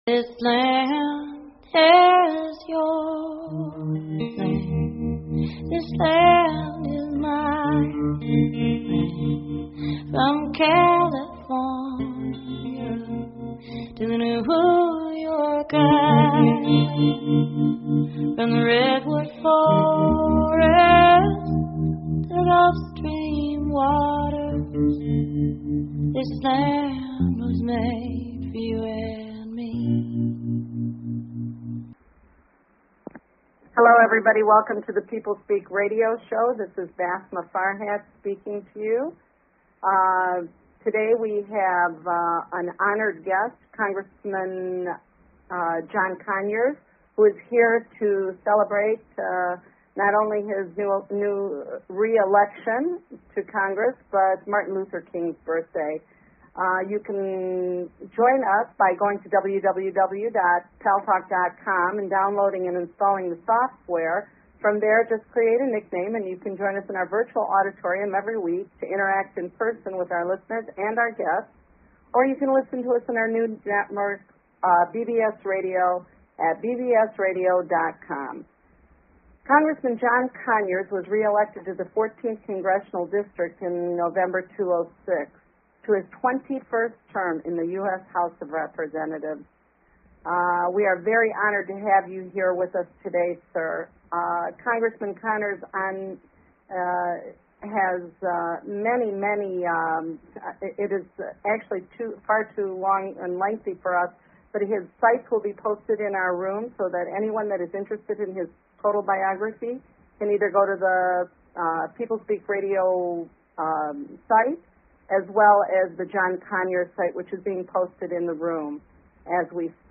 Guest, John Conyers